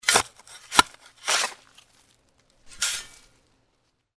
shovel1.mp3